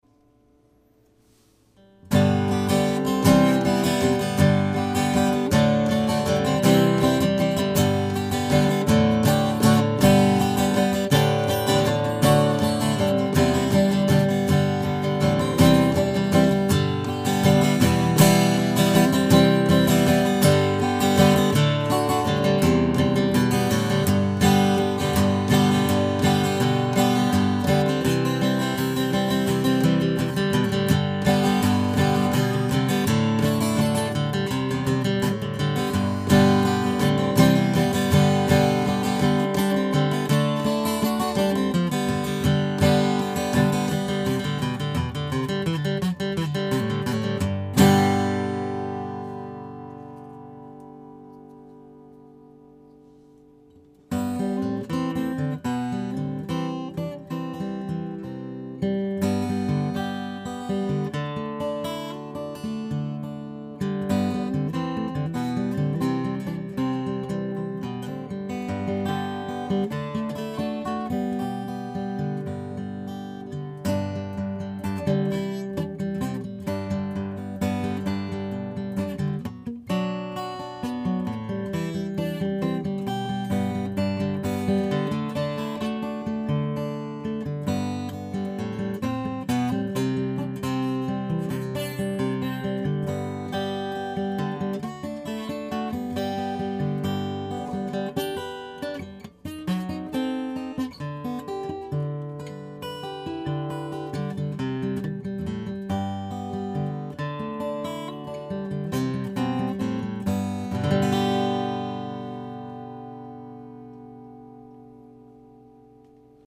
На нижнем торце практически ничего нет, а на верхнем мало.
Вот звуковой клип, который я сделал в первые 10 минут игры на гитаре:
(5 МБ, низкое качество)